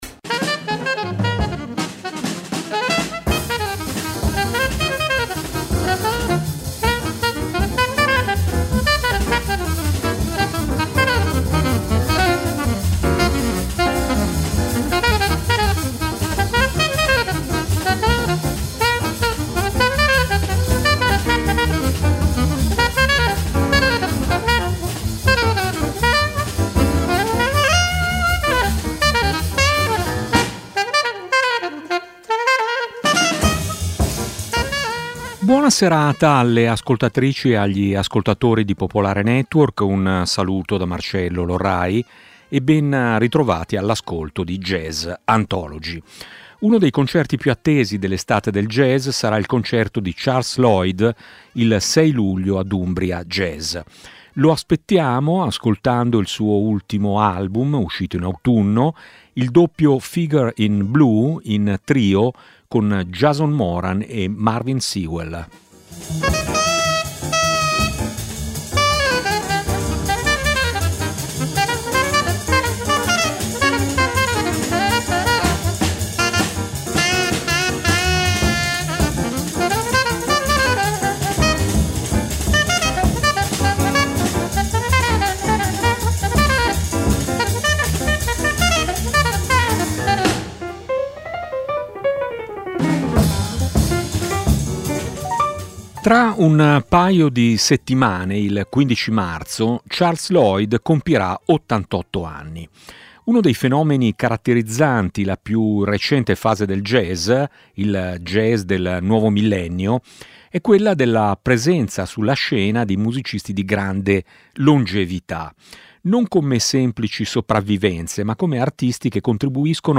"Jazz Anthology", programma storico di Radio Popolare, esplora la lunga evoluzione del jazz, dalla tradizione di New Orleans al bebop fino alle espressioni moderne.